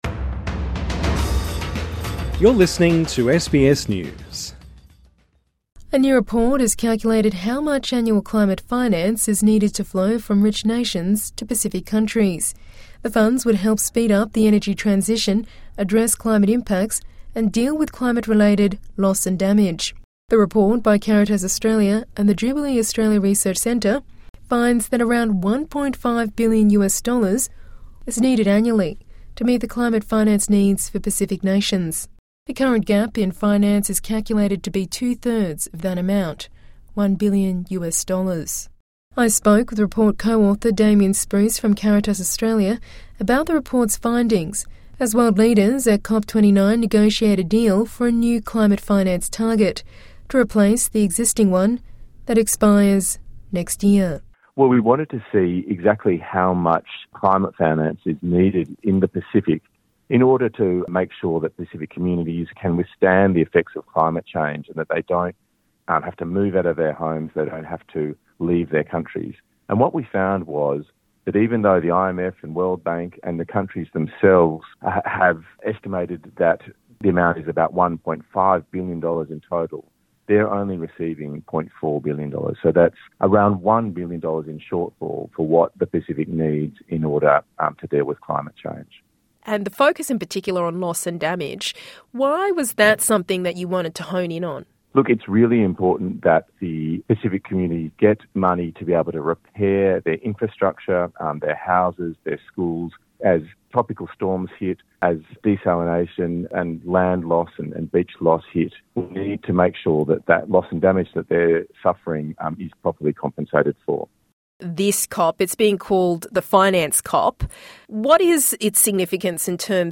INTERVIEW: Climate finance gap for Pacific nations revealed in new report